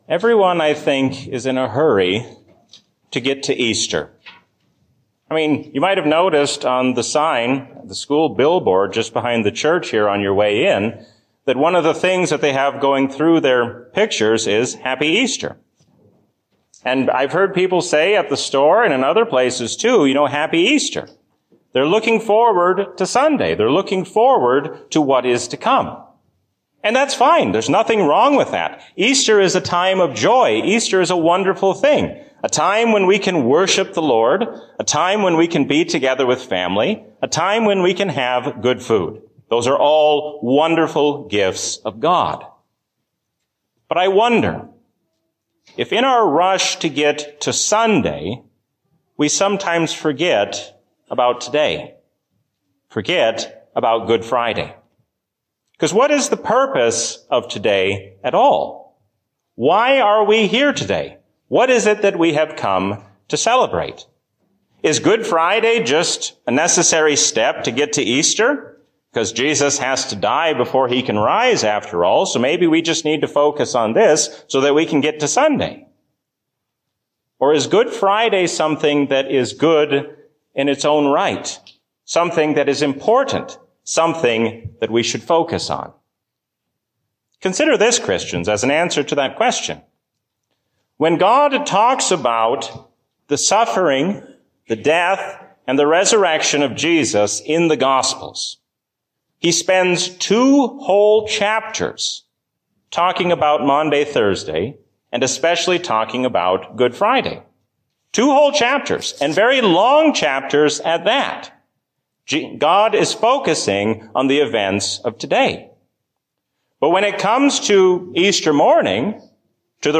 A sermon from the season "Trinity 2021." The world cannot help us, but God will give us a future greater than we can imagine.